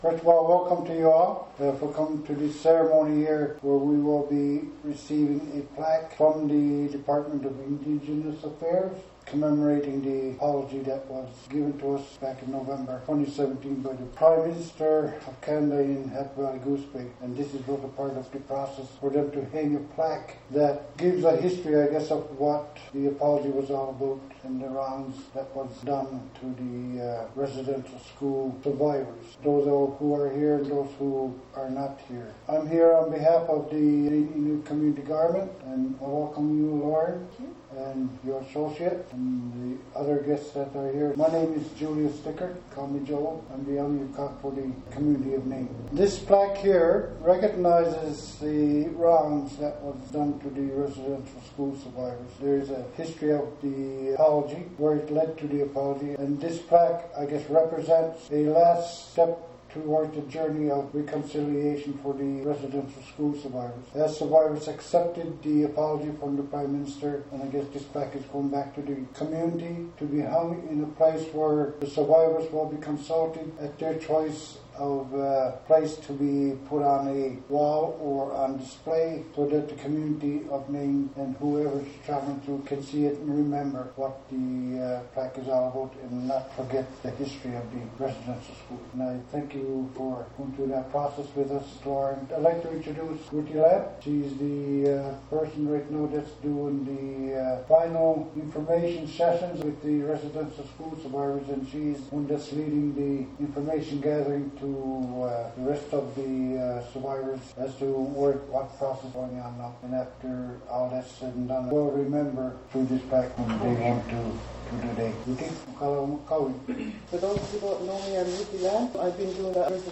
A commemorative plaque ceremony was held last Friday at the Pulâpvik Community room here in Nain on January 18, 2019.